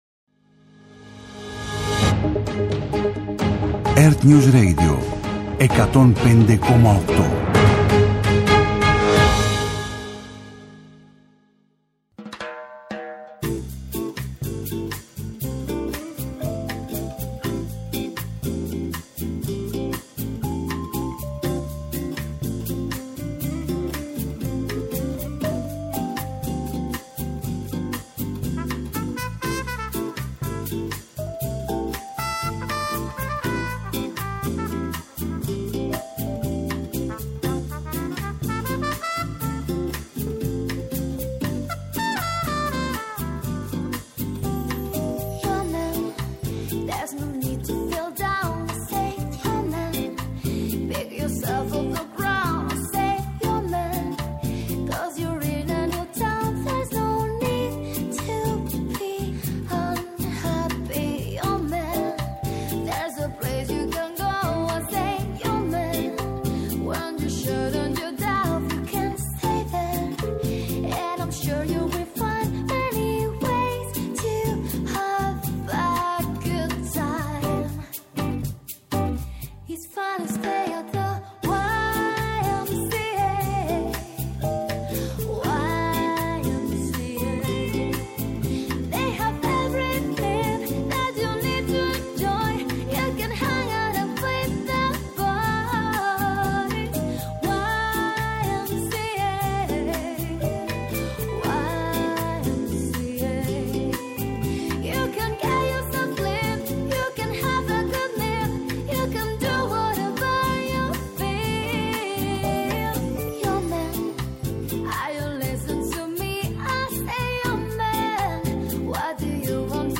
-Απόσπασμα από την ενημέρωση των πολιτικών συντακτών, από τον Κυβερνητικό Εκπρόσωπο, Παύλο Μαρινάκη